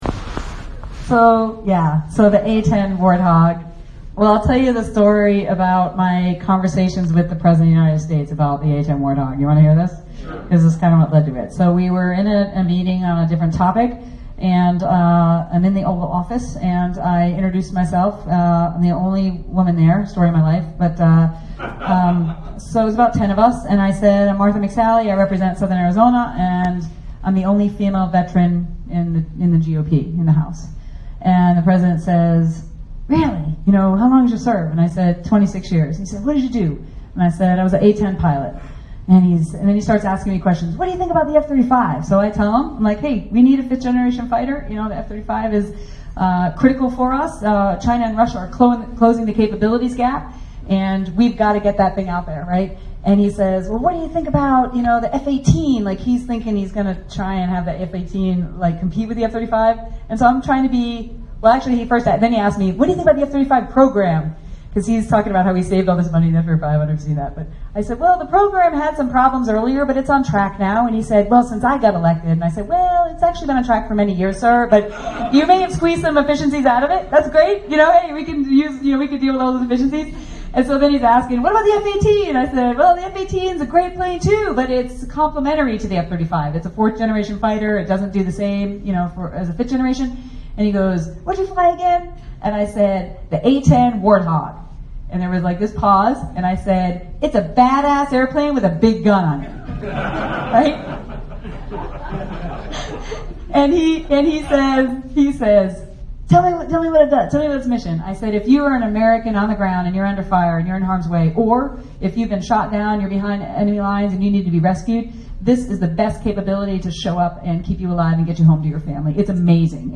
Earlier this year, Rep. Martha McSally recounted to the ÃÛèÖÖ±²¥ Bankers Association an Oval Office meeting at which she told Pres. Donald Trump about the A-10 Warthog. McSally's speech was secretly recorded by critics of her.